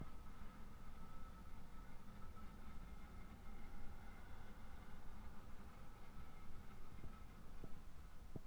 Ventilation 1.wav